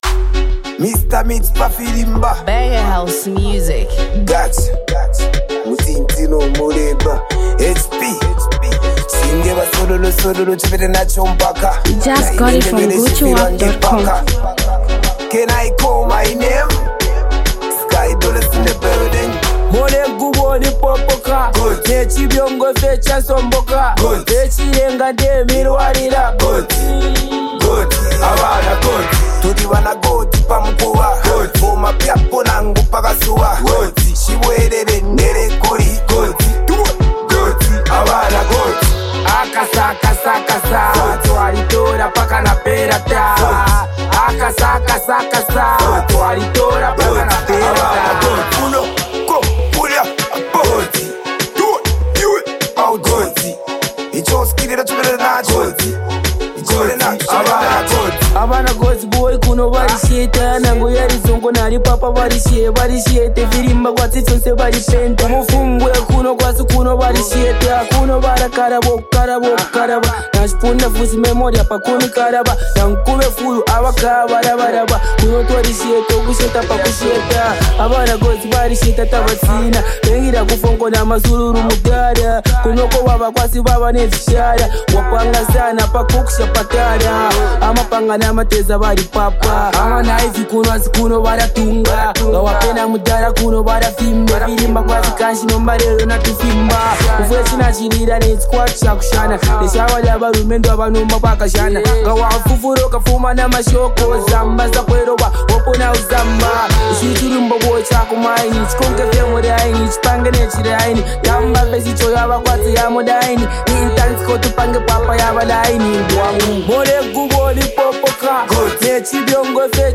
Zambian Mp3 Music
copperbelt music composer and rapper.
street anthem record